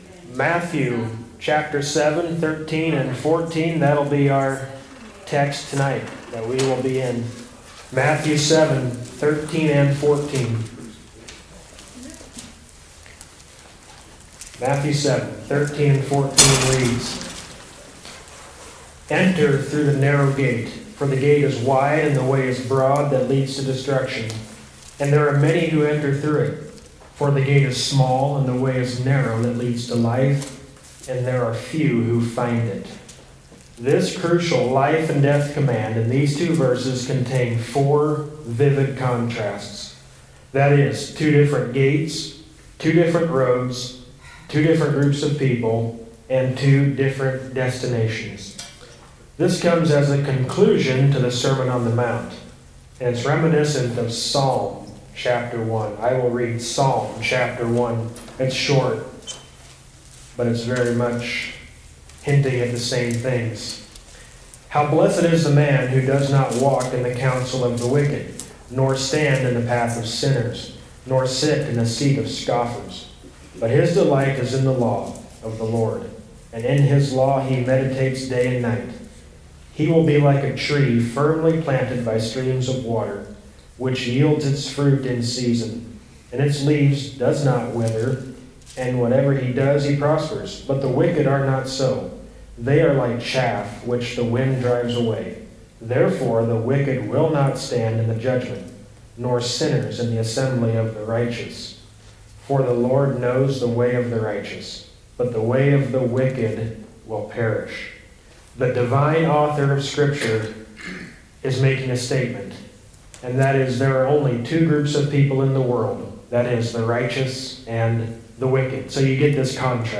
Wednesday Eve Bible Study | Matthew 7:13–14